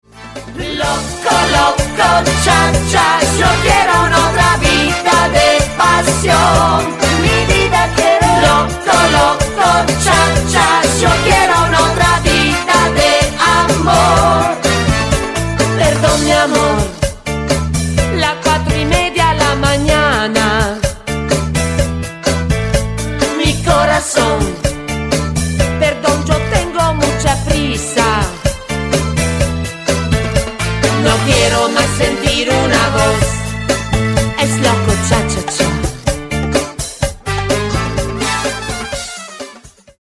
CHA CHA CHA  (3.49)